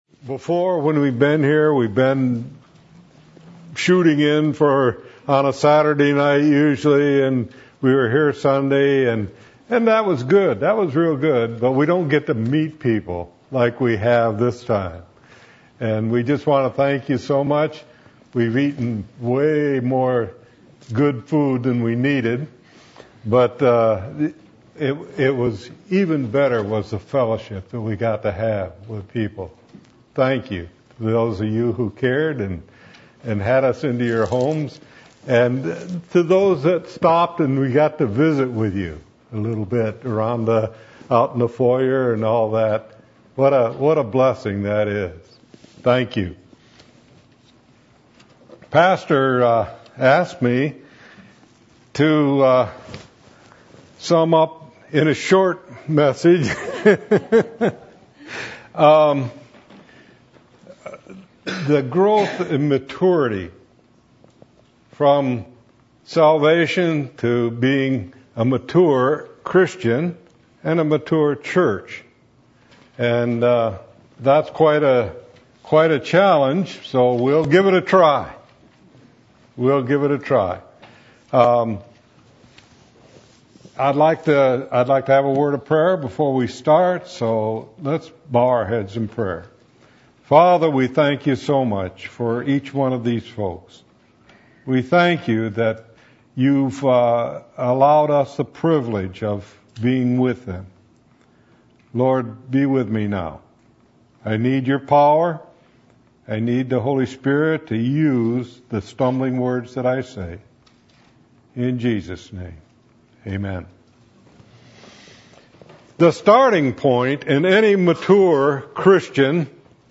Matthew 22:35-40 Service Type: Sunday Evening %todo_render% « For Whom Did Christ Die?